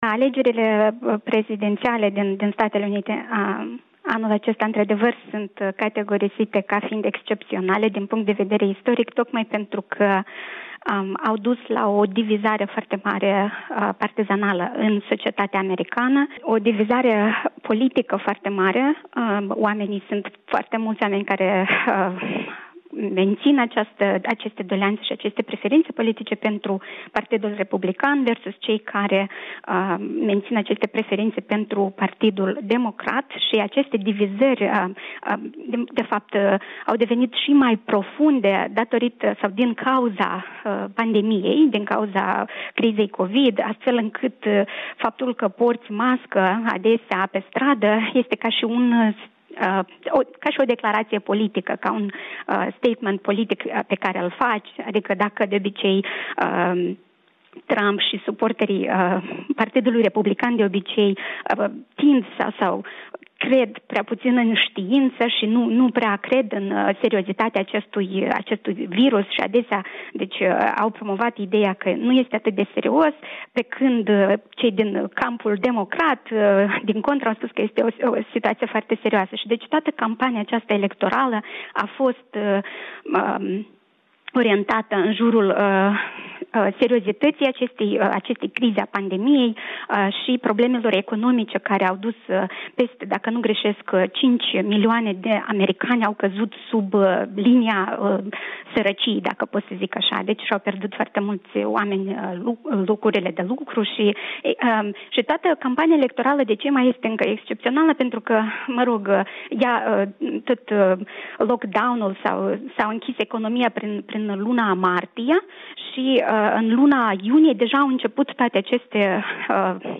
Interviu